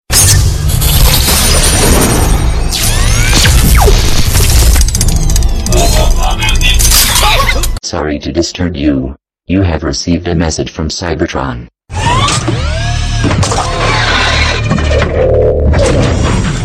Ringtones Category: Message